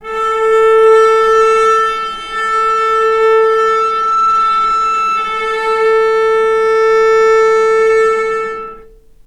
vc_sp-A4-mf.AIF